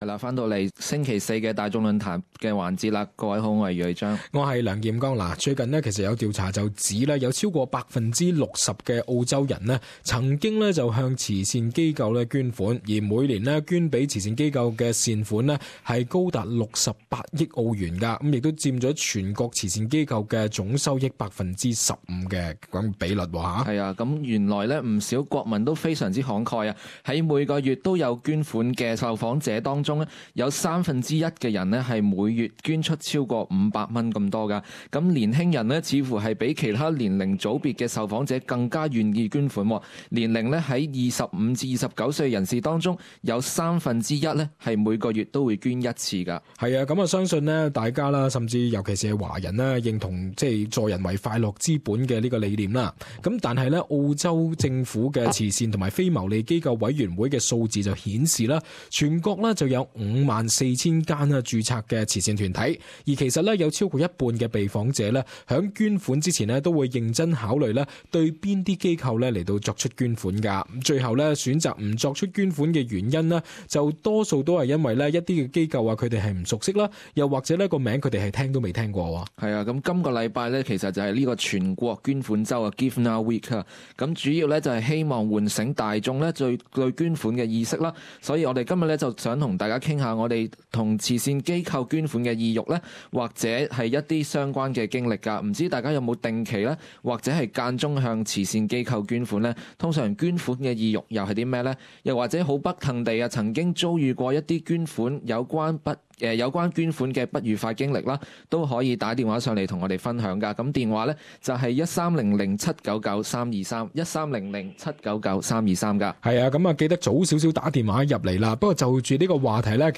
Talkback : “Give Now Week”